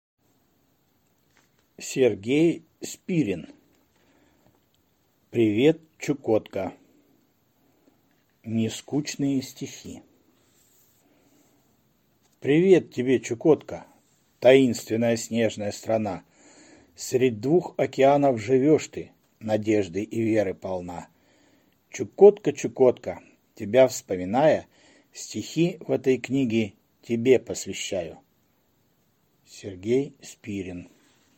Аудиокнига Привет, Чукотка!!! Нескучные стихи | Библиотека аудиокниг